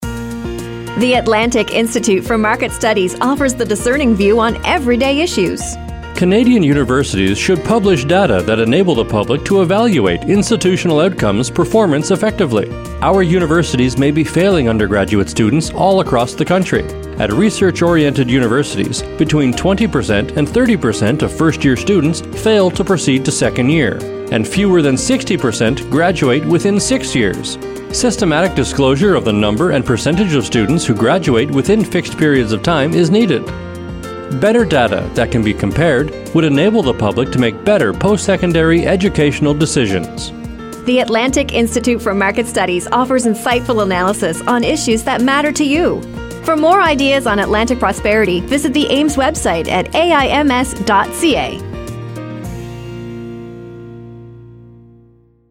Radio: Fixing Undergraduate Education -